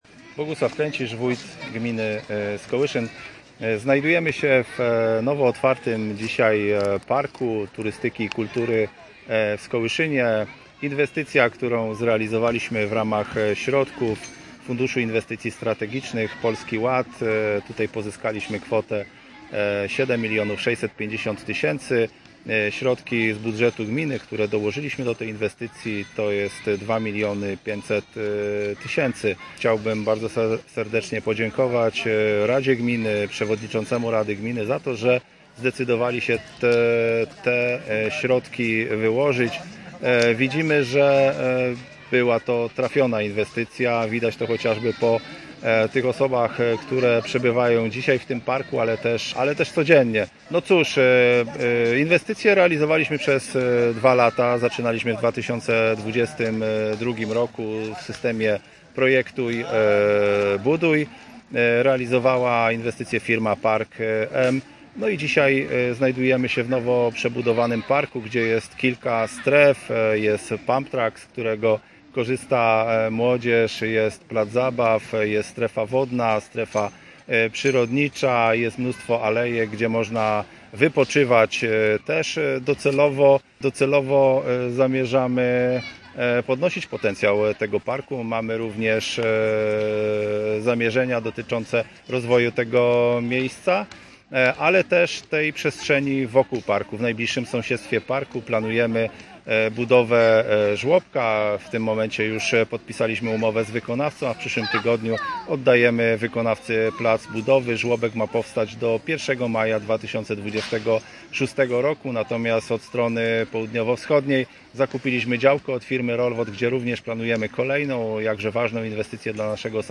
W niedzielne popołudnie dokonano oficjalnego otwarcia Parku Turystyki i Kultury w Skołyszynie.
Oficjalnego otwarcia i przekazania parku do użytkowania dokonał wójt gminy Skołyszyn Bogusław Kręcisz wraz z przewodniczącym Rady Gminy Dariuszem Łyszczarzem, radnymi, swoimi najbliższymi współpracownikami oraz zaproszonymi gośćmi.